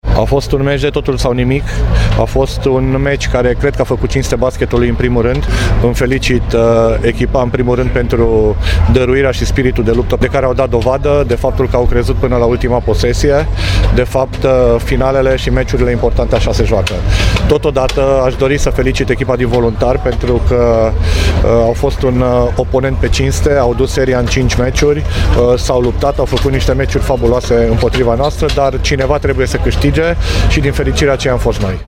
a felicitat ambele echipe, la final de meci: